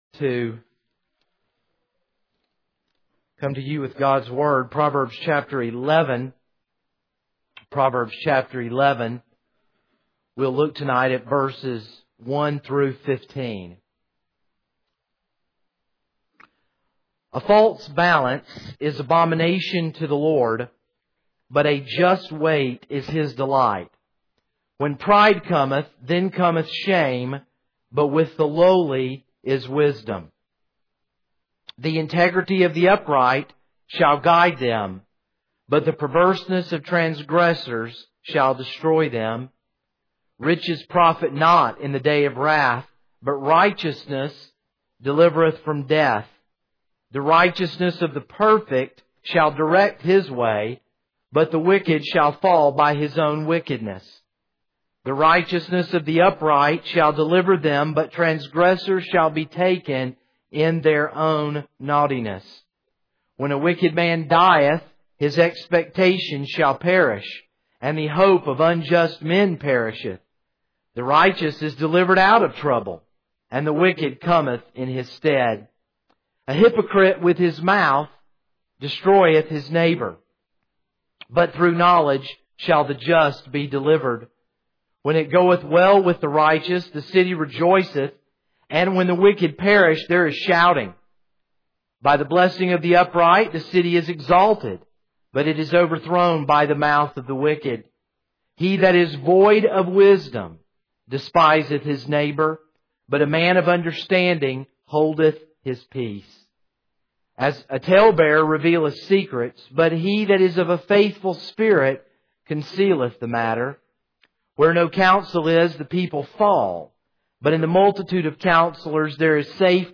This is a sermon on Proverbs 11:1-15.